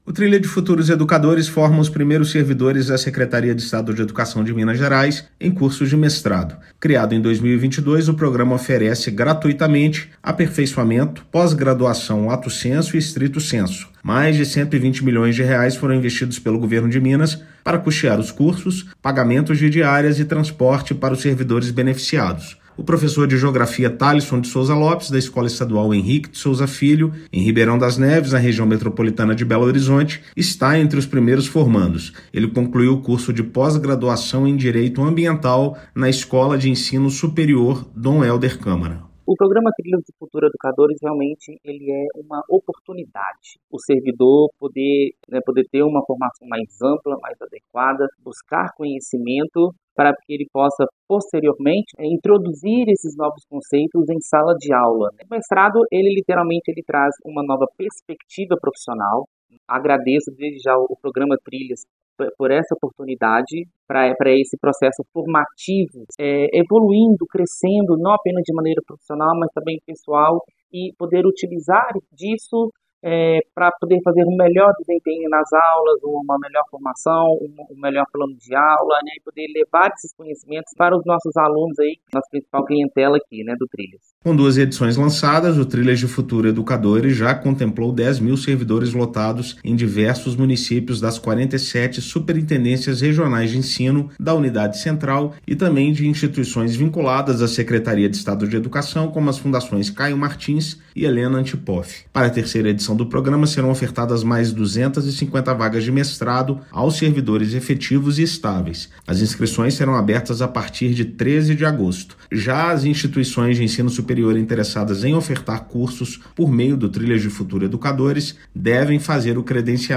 Mais de R$120 milhões estão sendo investidos pelo Governo de Minas no projeto, que financia os cursos, além de estadia e transporte dos servidores beneficiados. Ouça matéria de rádio.